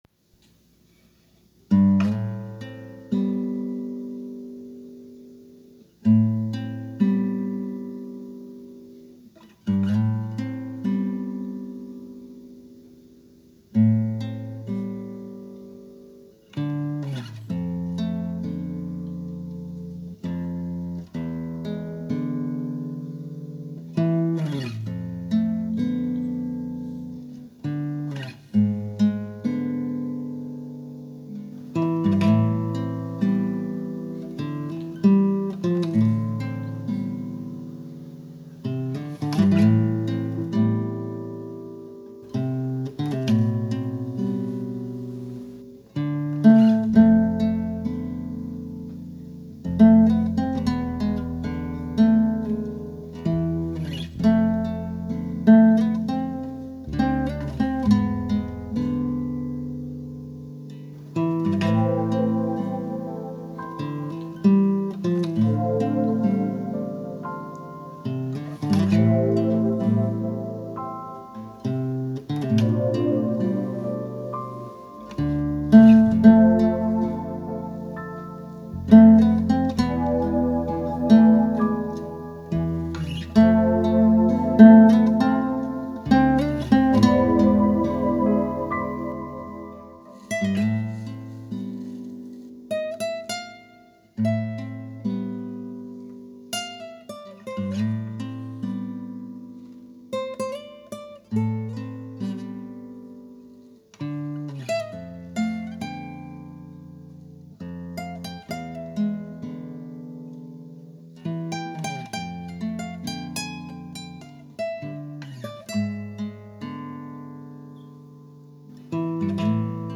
The Heaviest Burden (guitar-based piece for a short film)
He wanted it all played with real instruments so I had to work with what I had, a cheap classical guitar and an digital piano.